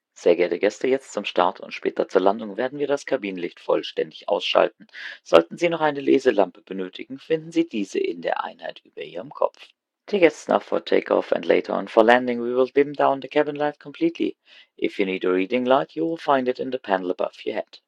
CabinDimTakeoff[Evening].ogg